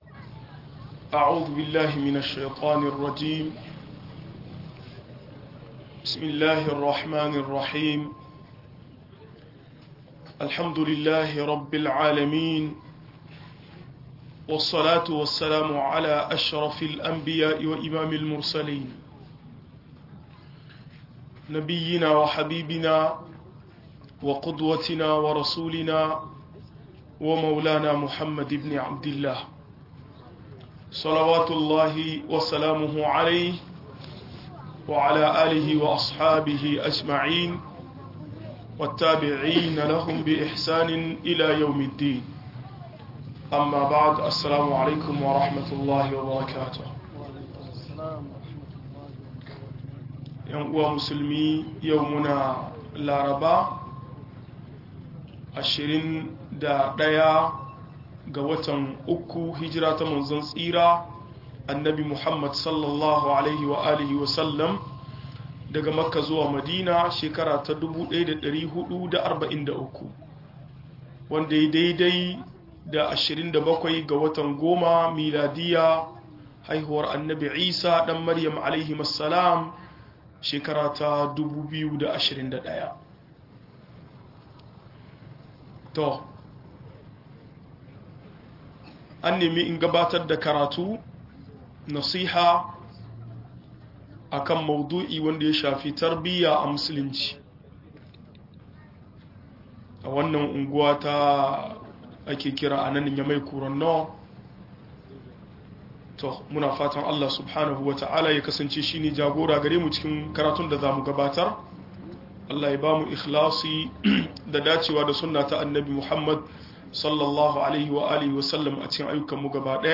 Salon bin matakai a tarbiyya - MUHADARA